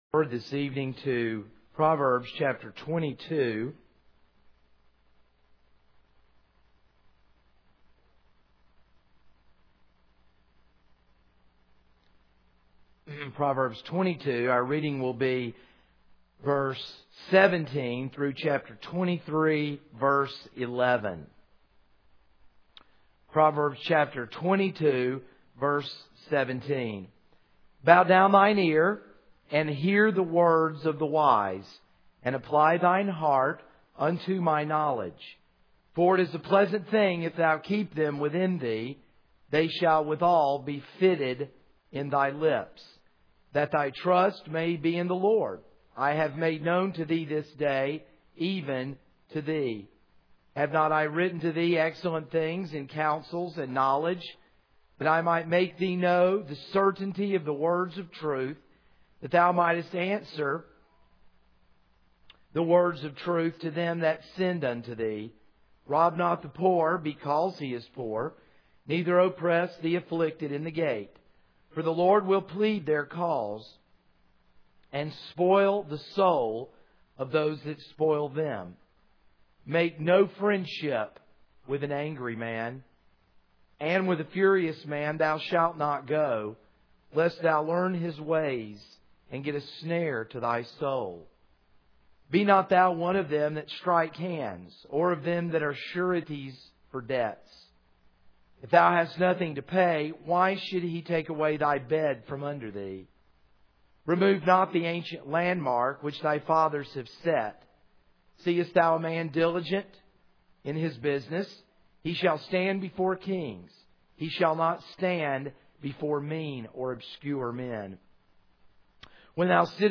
This is a sermon on Proverbs 22:17-23:11.